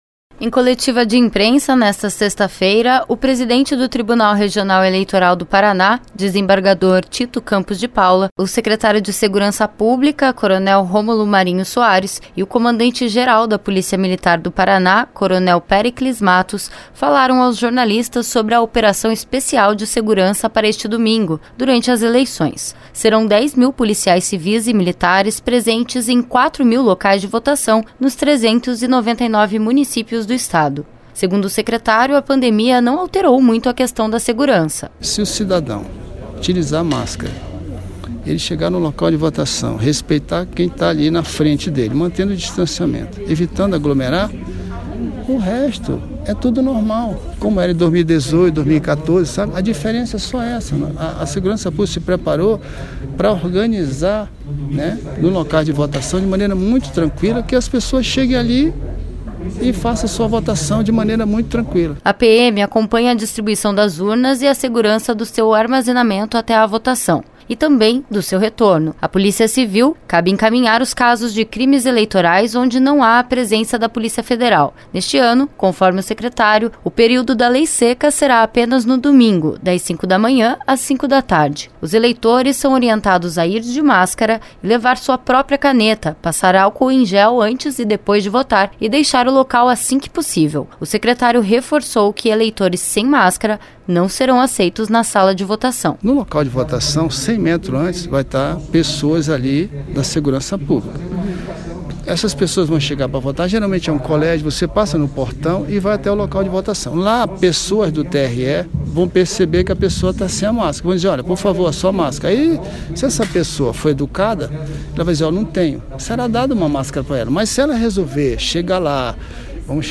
Os detalhes na reportagem.
Em coletiva de imprensa nesta sexta-feira, o presidente do Tribunal Regional Eleitoral do Paraná, desembargador Tito Campos de Paula, o Secretário de Segurança Pública do Paraná, Coronel Rômulo Marinho Soares, e o Comandante geral da Polícia Militar do Paraná, Coronel Péricles Matos, falaram aos jornalistas sobre a operação especial de segurança para este domingo, durante as eleições.